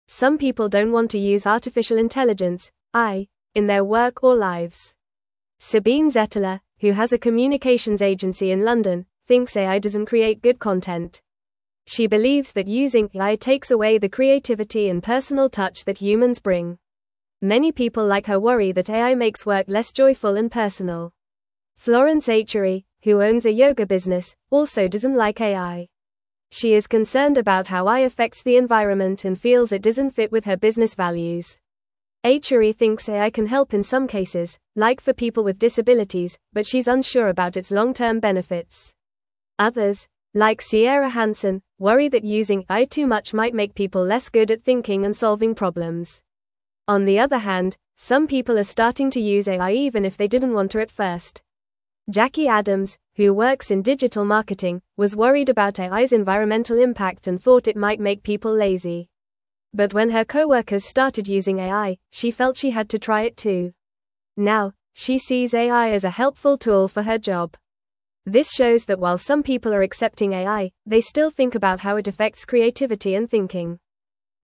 Fast
English-Newsroom-Lower-Intermediate-FAST-Reading-People-Worry-About-AI-and-Creativity-and-Connection.mp3